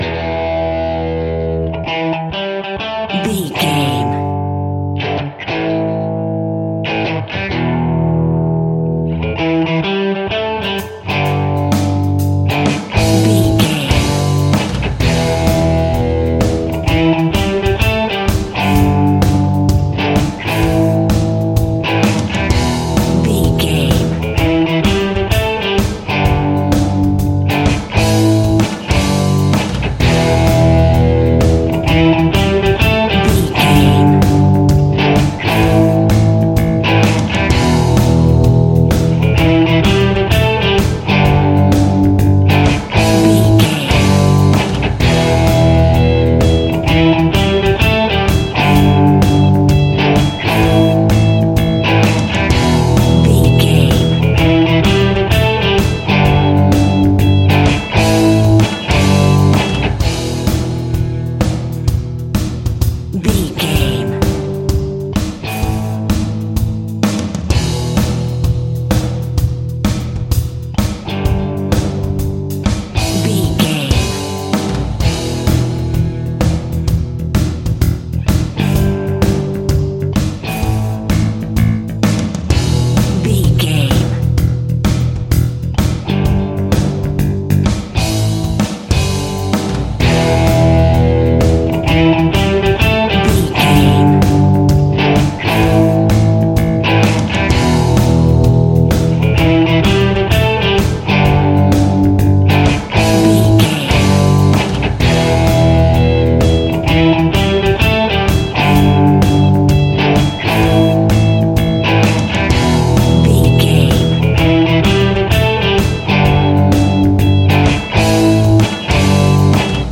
Ionian/Major
energetic
driving
aggressive
electric guitar
bass guitar
drums
hard rock
heavy metal
blues rock
distorted guitars
hammond organ